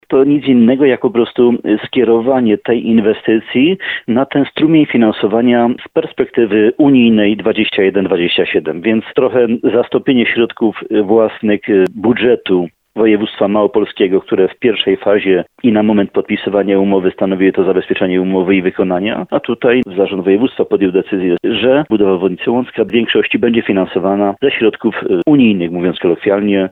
Dlaczego dopiero teraz przyznano dofinansowanie, skoro już wcześniej rozpoczęły się przygotowania do inwestycji? Wyjaśnia Jan Dziedzina, wójt gminy Łącko.